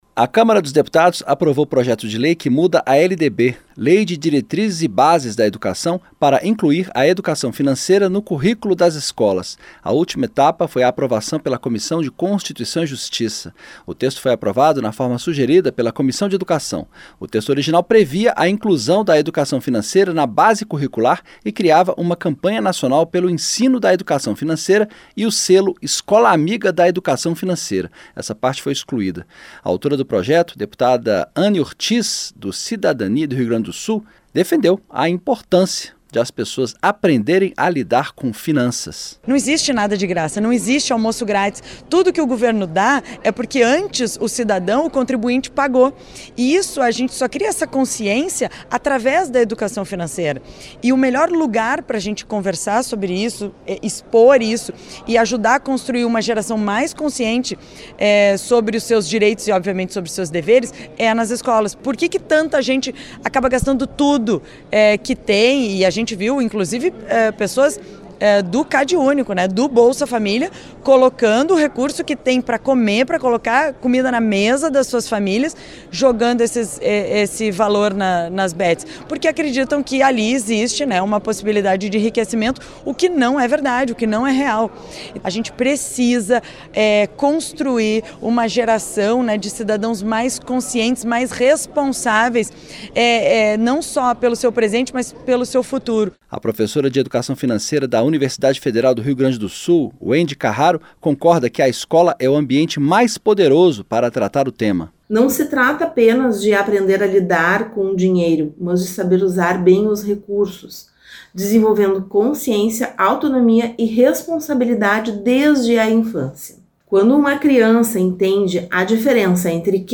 A CÂMARA APROVOU UM PROJETO QUE INCLUI A EDUCAÇÃO FINANCEIRA NO CURRÍCULO DAS ESCOLAS. ESPECIALISTAS DESTACAM A IMPORTÂNCIA DE, DESDE CEDO, AS CRIANÇAS APRENDEREM A LIDAR COM FINANÇAS. A REPORTAGEM